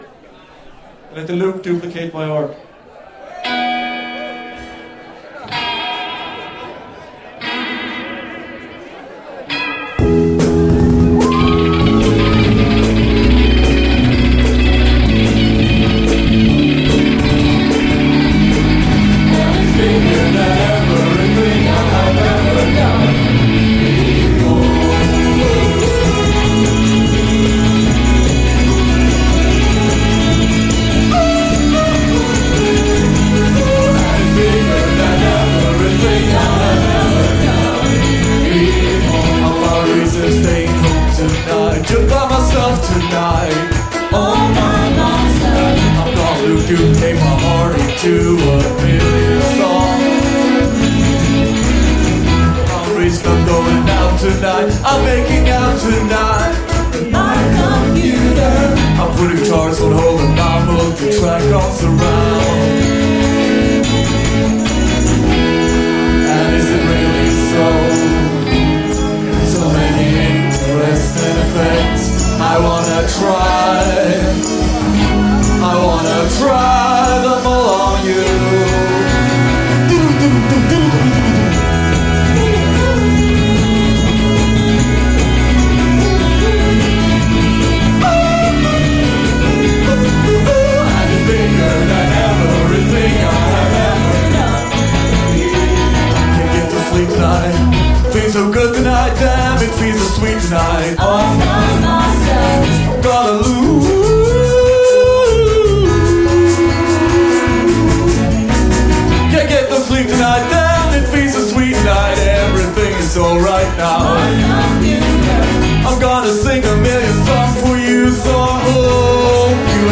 Live @ Kägelbanan, Stockholm 2010-10-29